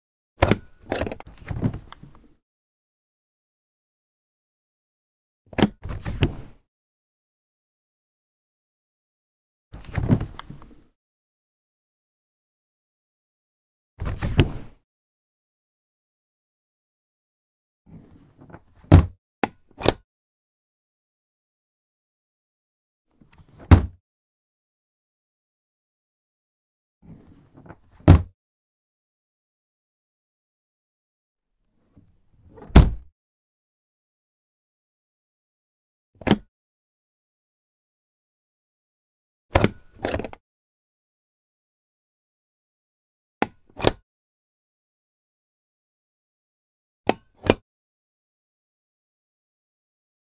household
Flight Case Unlatch and Open Lid